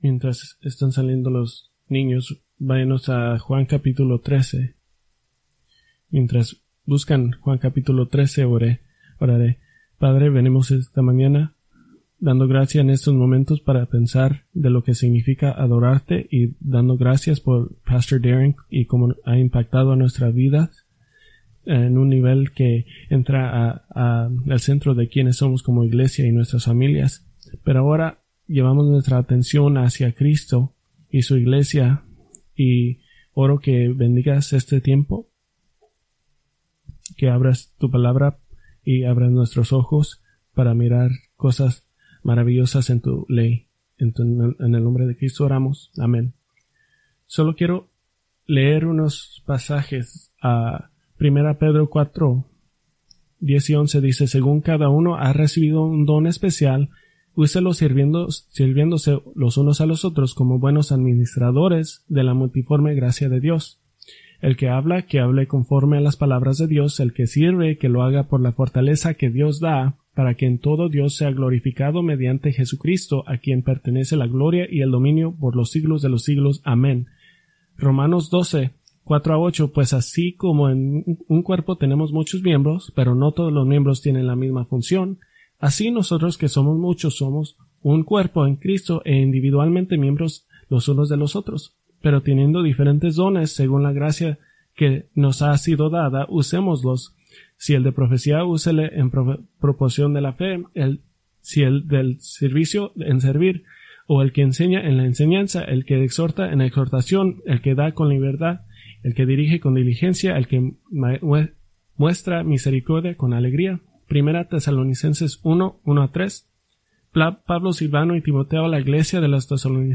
Preached May 26, 2024 from Escrituras seleccionadas